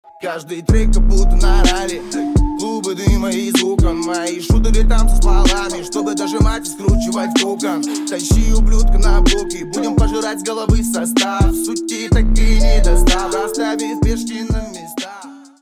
• Качество: 320, Stereo
громкие
русский рэп
качающие
злые